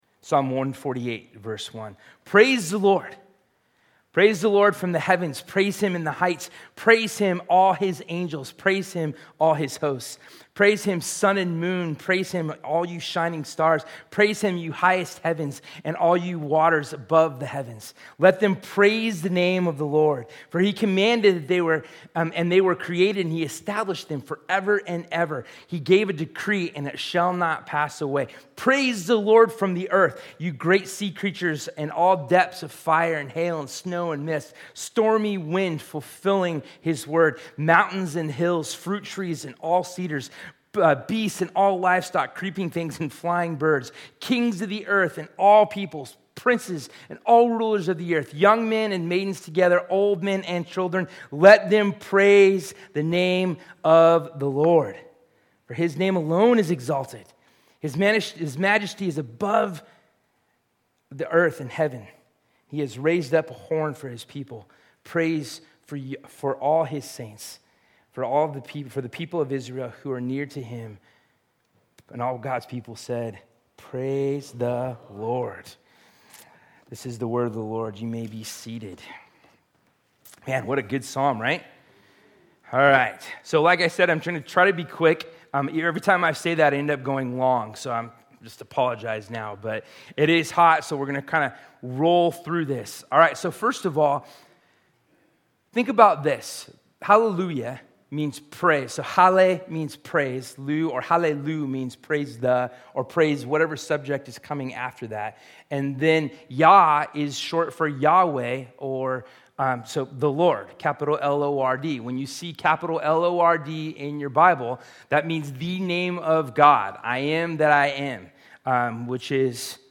Sermon Notes Pause / Psalm 148 Honesty, Authentic, Real, Down to Earth.